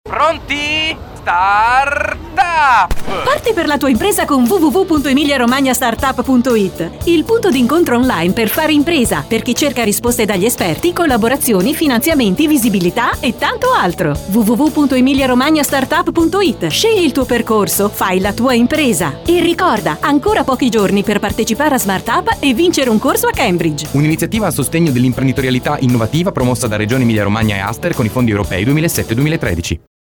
Ideazione, script e produzione di uno spot radiofonico regionale di 30" per la promozione dell'iniziativa "Emilia Romagna Start Up".
Spot-Radio-Start-Up-Regione-Emilia-Romagna.mp3